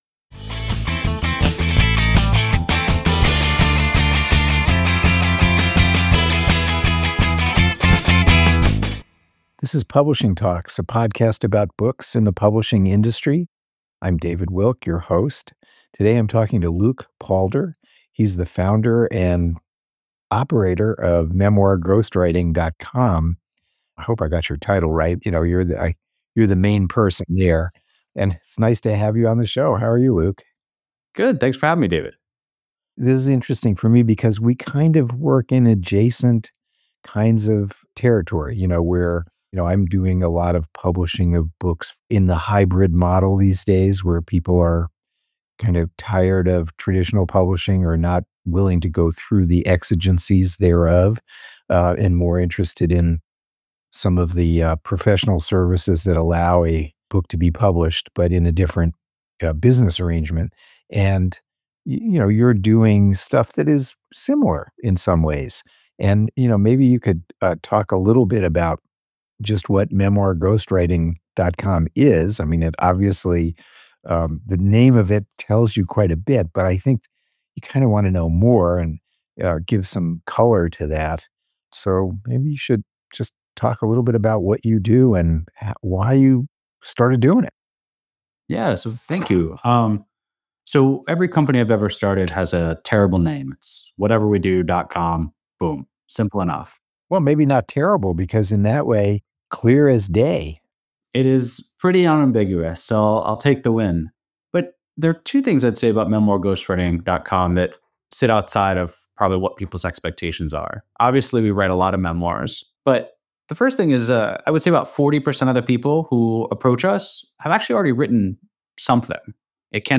Publishing Talks Interview